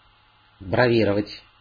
Ääntäminen
IPA: [ku.ʁa.ʒø]